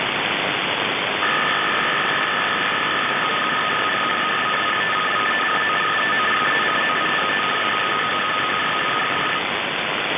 Начало » Записи » Радиоcигналы на опознание и анализ